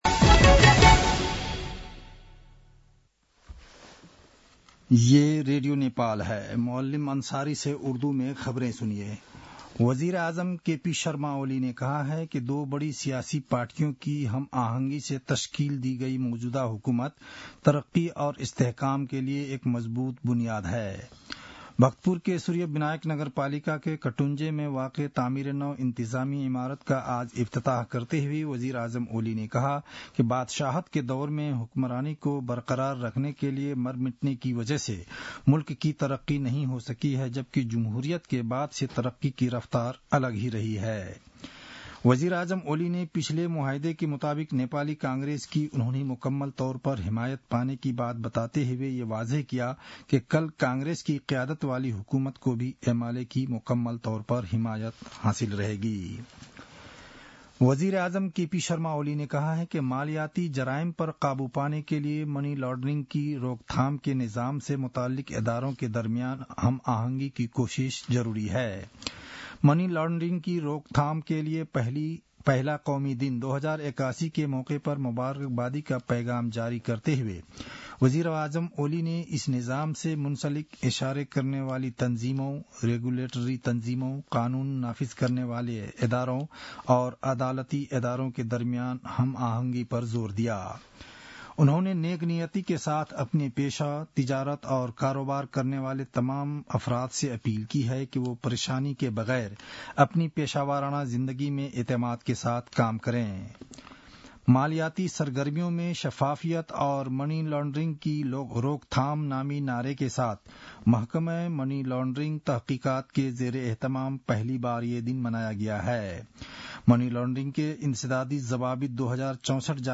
An online outlet of Nepal's national radio broadcaster
उर्दु भाषामा समाचार : १५ माघ , २०८१
Urdu-news-10-14.mp3